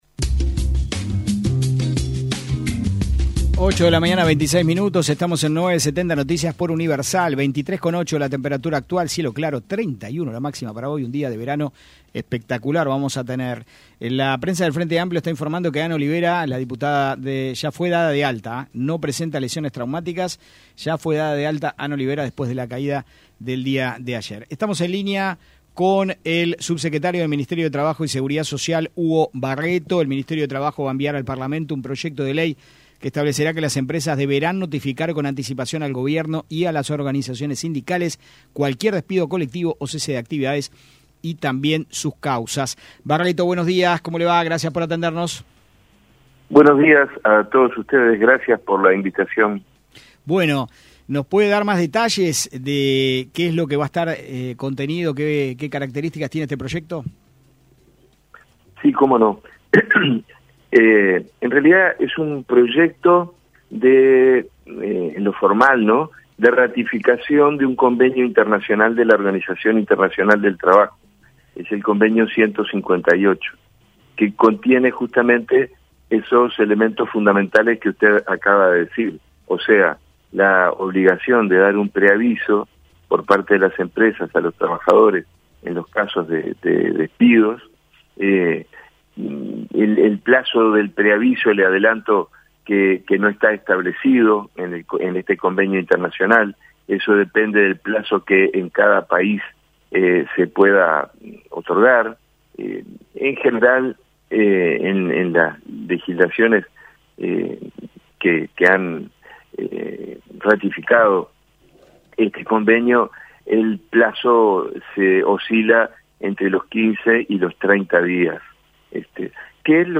El subsecretario del Ministerio de Trabajo y Seguridad Social (MTSS), Hugo Barretto en entrevista con 970 Noticias explicó las principales características del proyecto que enviarán al Parlamento en donde se establecerá que las empresas deberán notificar con anticipación al gobierno y a las organizaciones sindicales cualquier despido colectivo o cese de actividades, así como sus causas.